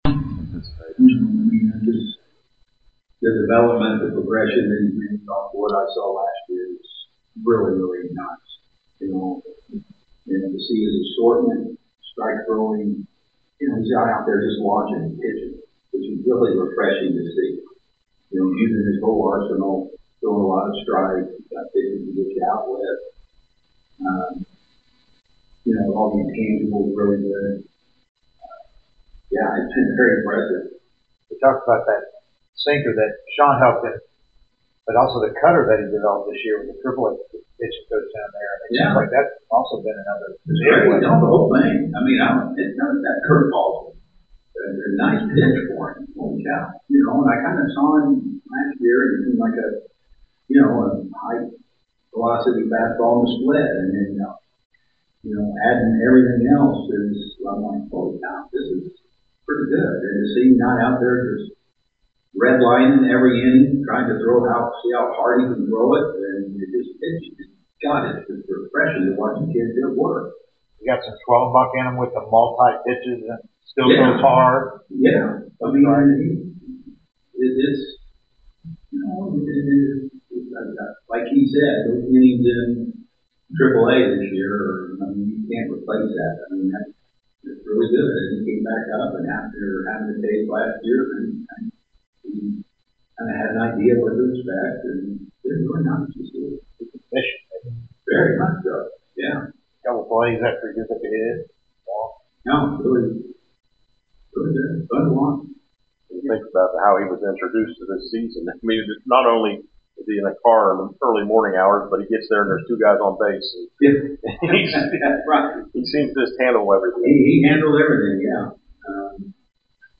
08-20-25 Atlanta Braves Manager Brian Snitker Postgame Interview